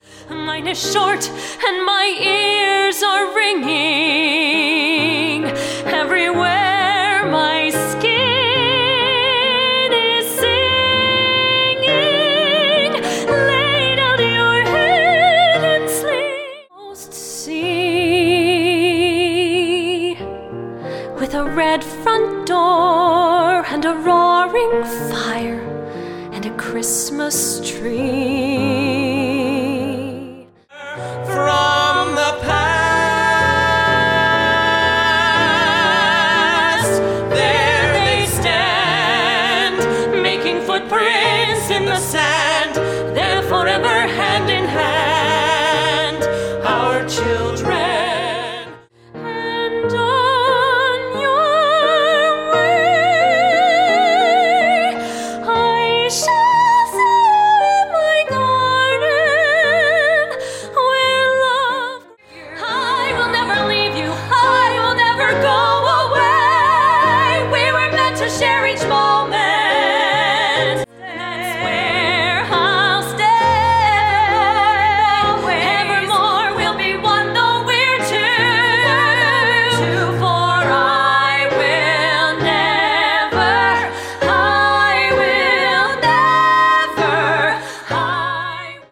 Singing Demo